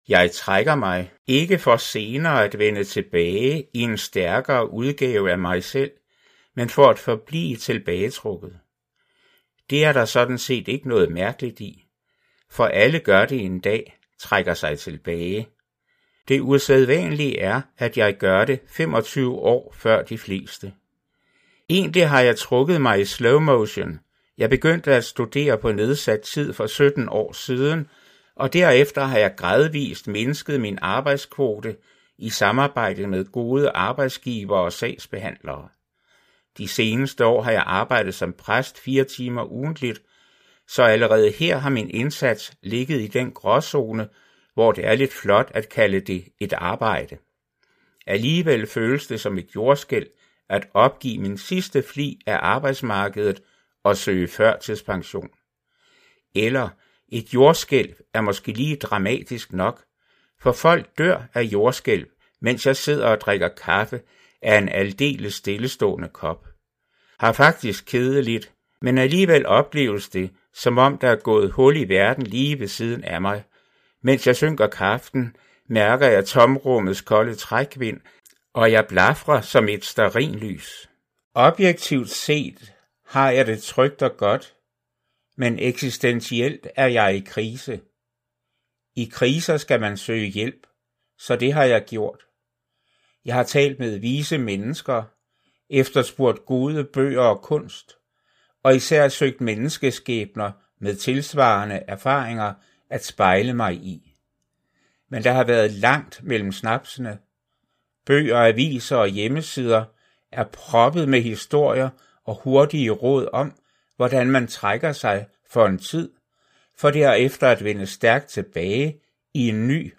Kærlighedens 5 sprog af Gary Chapman - MP3-lydbog